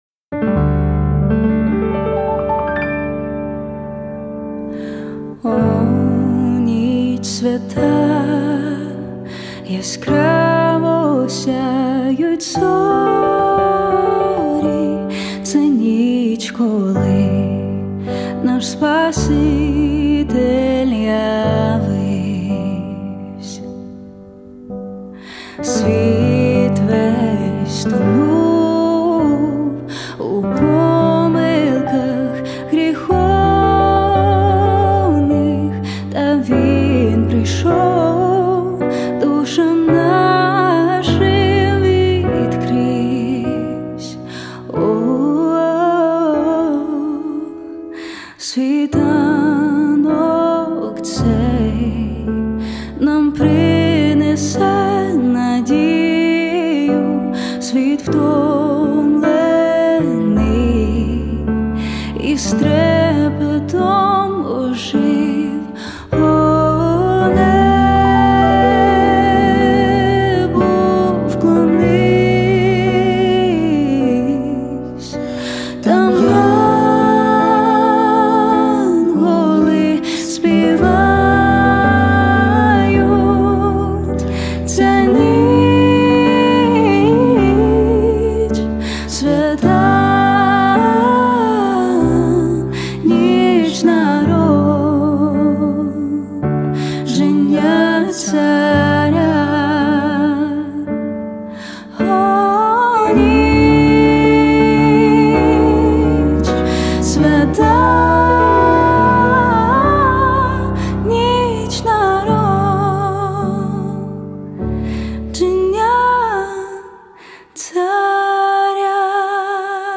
песня
2 куплет: нема в виконанні